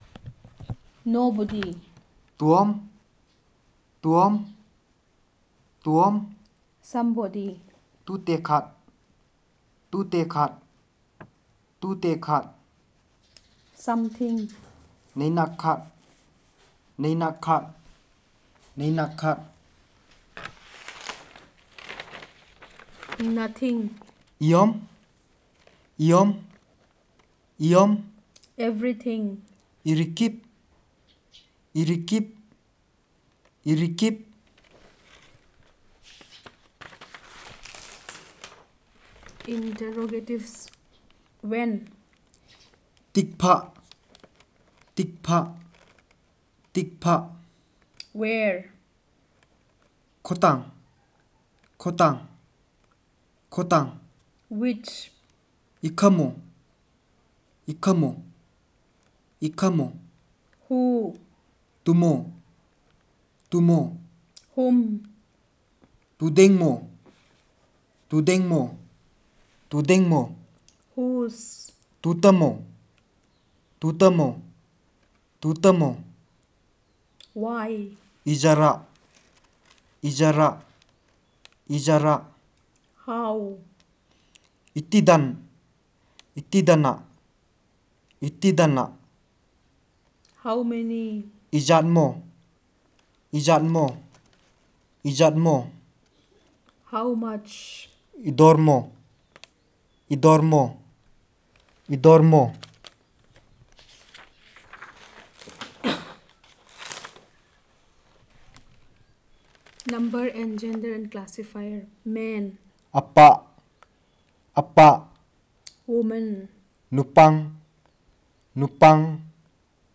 Narration of stories about possession and exorcism, oral history, contact with animals in the forest, experience in the profession, etc.